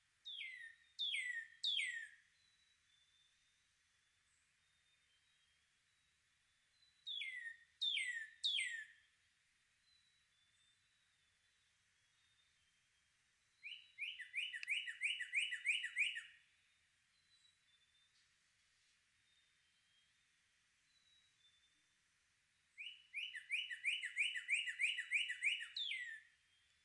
早晨繁忙的鸟叫
描述：重庆乡村早晨鸟叫的声音。
标签： 鸟叫 早晨 乡村
声道单声道